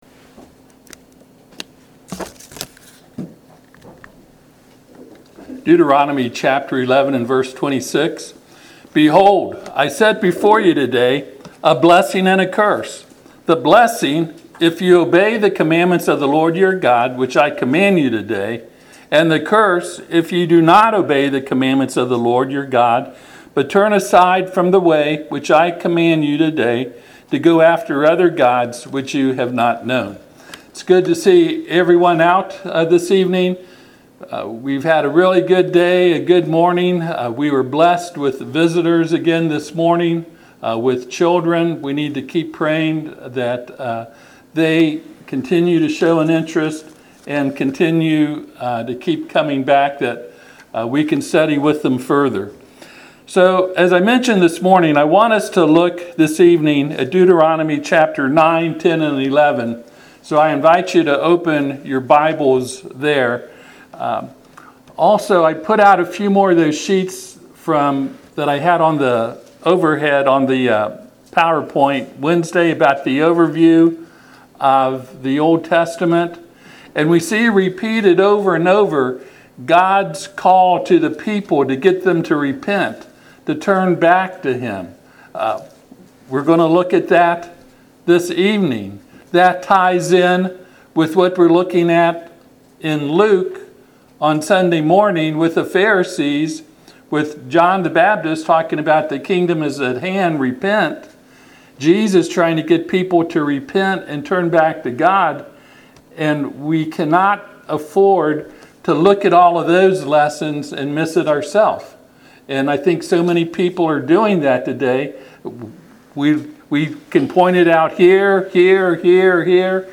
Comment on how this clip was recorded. Passage: Deuteronomy 11:26-28 Service Type: Sunday PM